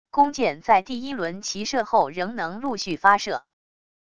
弓箭在第一轮齐射后仍能陆续发射wav音频